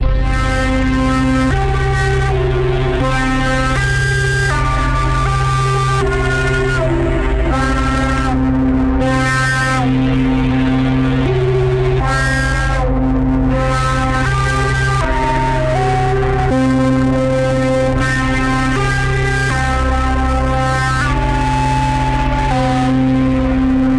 硬工业Aphex电子环路加工合成器Techno Hardcore Buzzes Drones Atmospheres